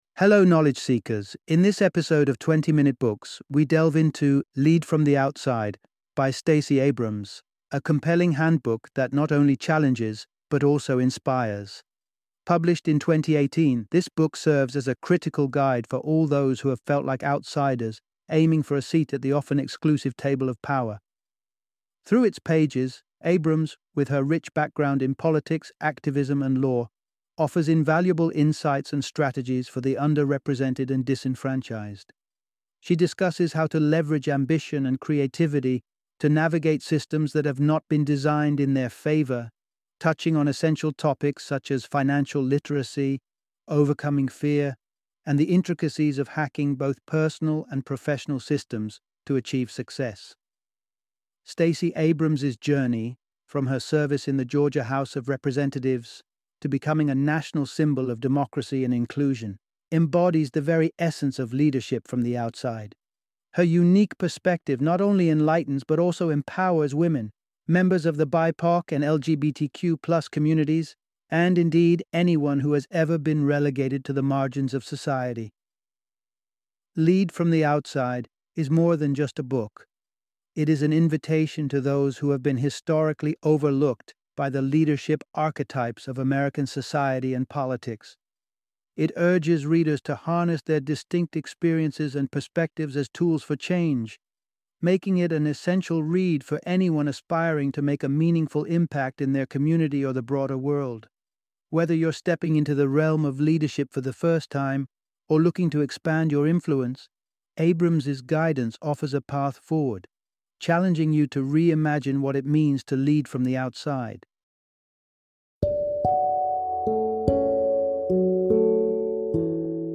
Lead from the Outside - Audiobook Summary